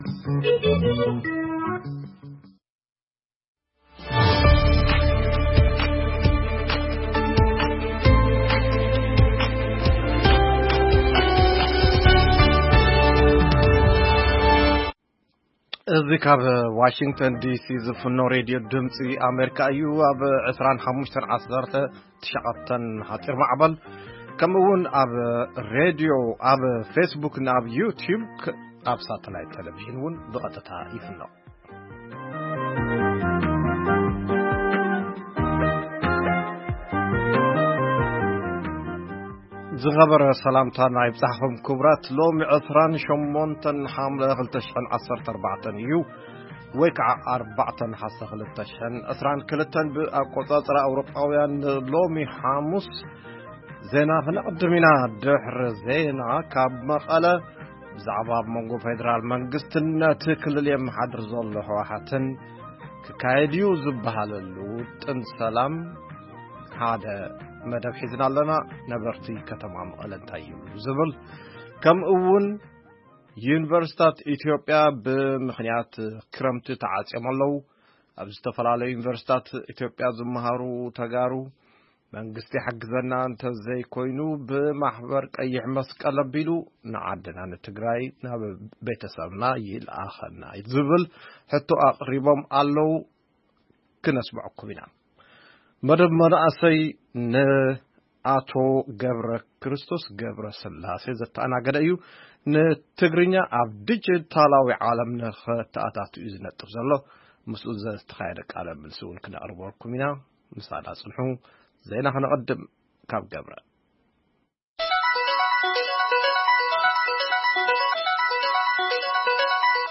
ዜና
ቃለ መጠይቕ